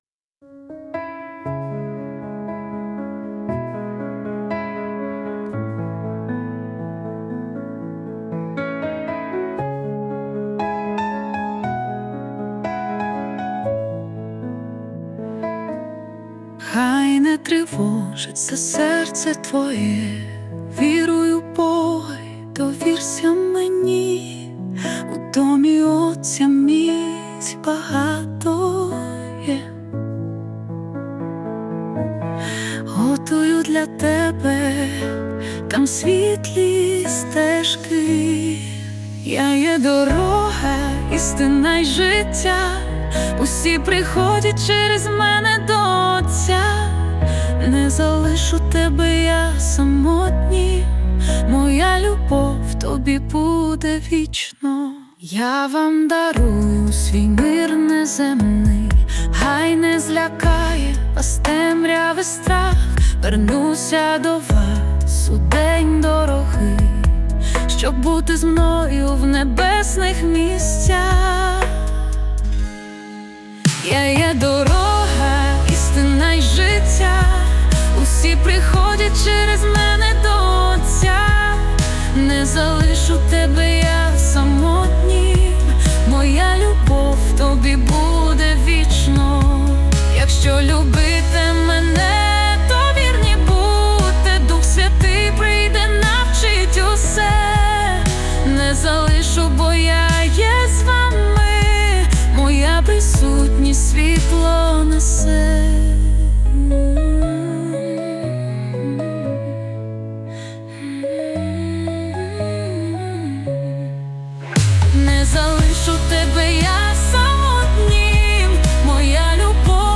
песня ai
Jesus Worship